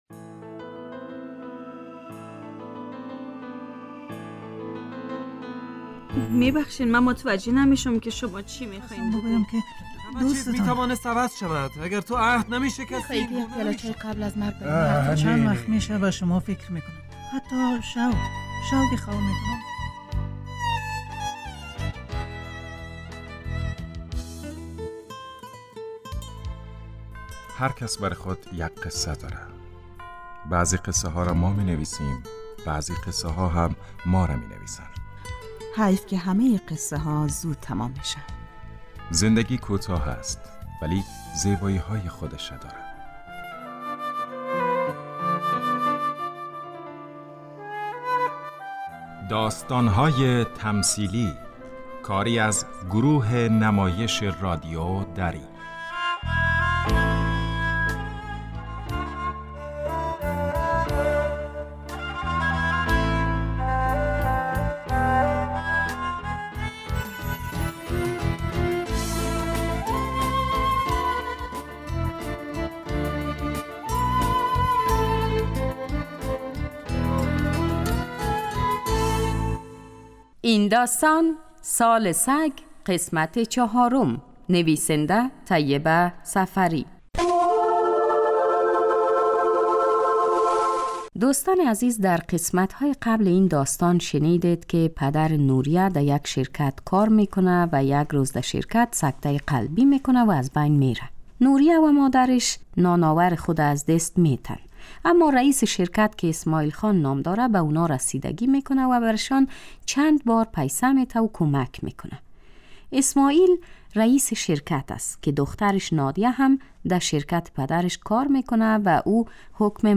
داستان تمثیلی / سال سگ